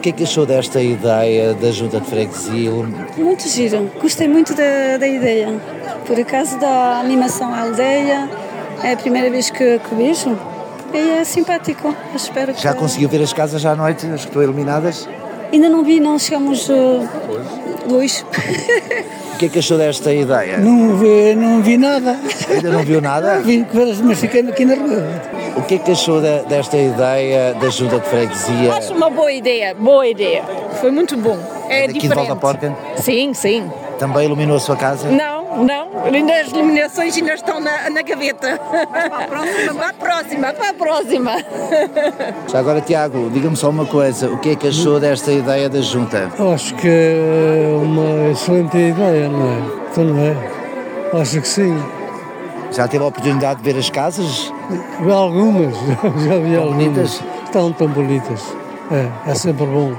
À volta da fogueira, os habitantes e os emigrantes que já chegaram para passarem o natal gostaram desta iniciativa: